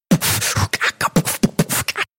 Звуки битбокса